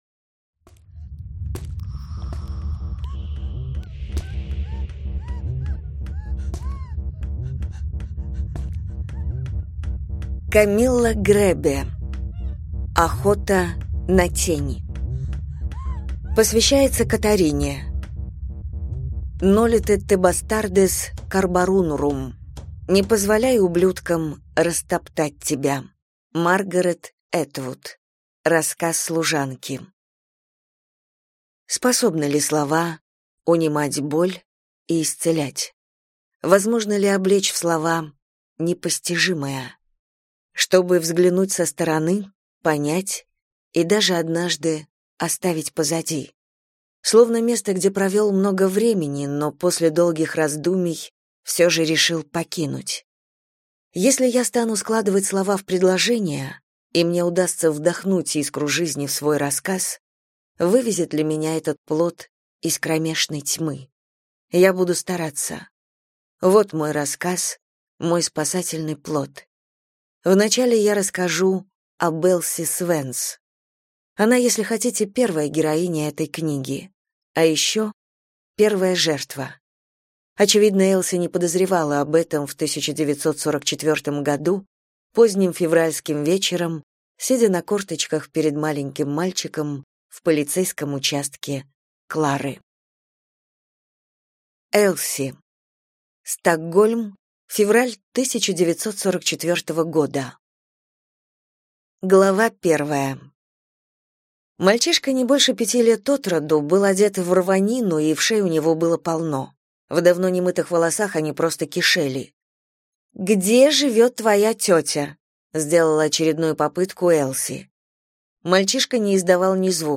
Аудиокнига Охота на тень | Библиотека аудиокниг